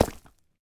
Minecraft Version Minecraft Version latest Latest Release | Latest Snapshot latest / assets / minecraft / sounds / block / deepslate / step6.ogg Compare With Compare With Latest Release | Latest Snapshot
step6.ogg